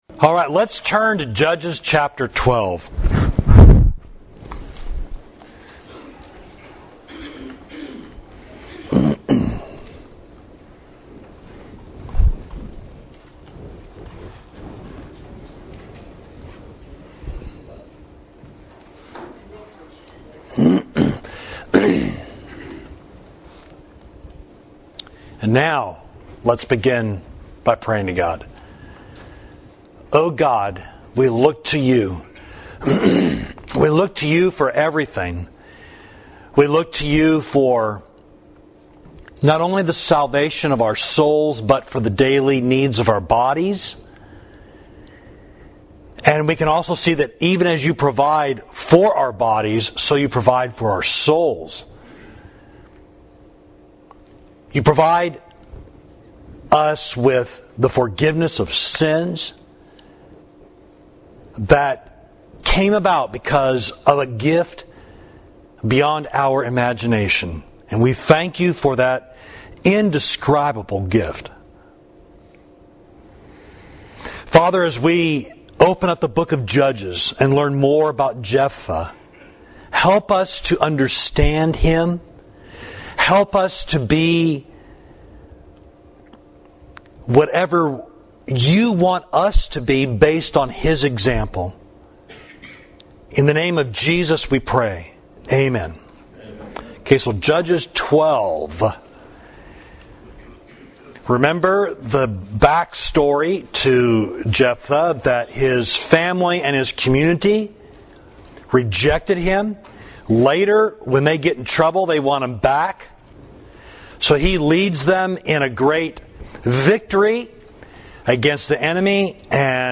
Class: Taking Our Lives in Our Hands, Judges 12